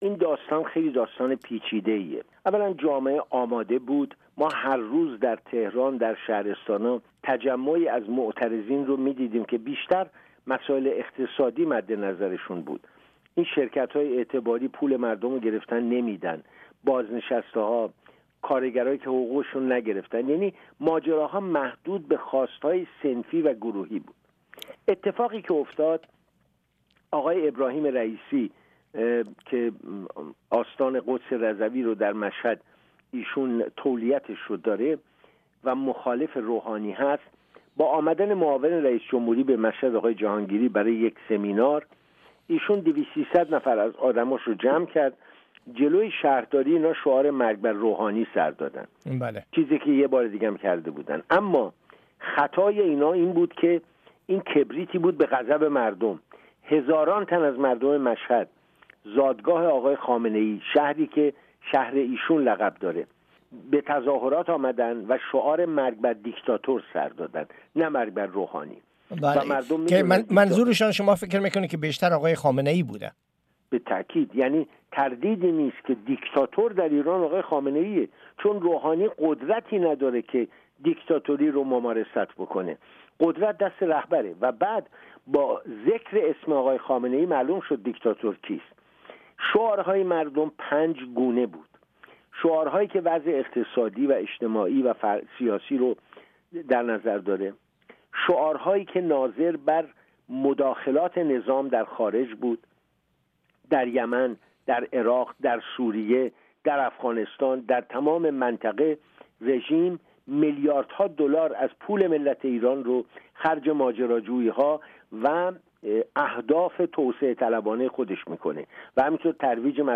جزئیات مصاحبه با آقای علرضا نوری زاده، رئیس مرکز مطالعات ایران و عرب در اینجا بشنوید